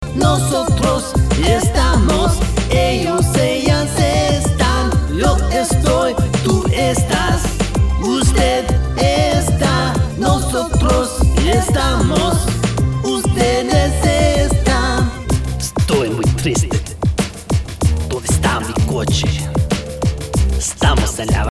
Spanish CD or Album Download